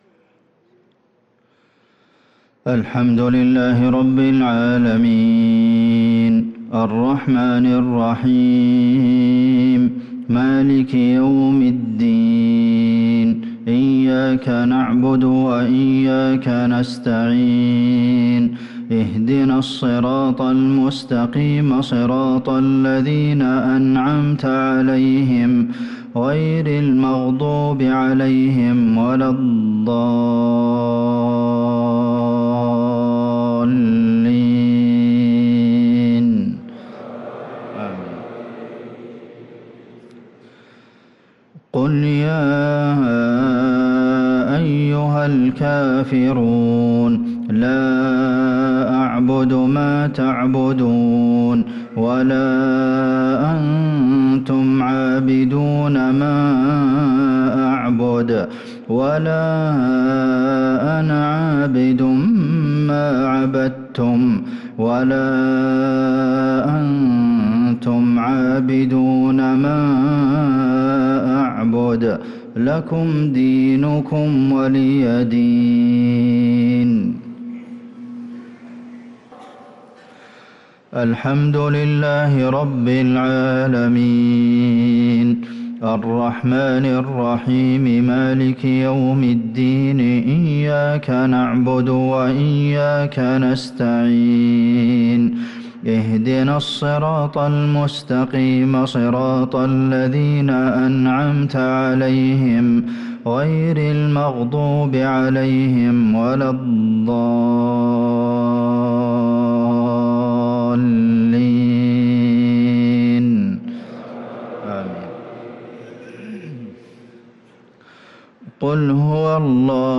صلاة المغرب للقارئ عبدالمحسن القاسم 3 محرم 1445 هـ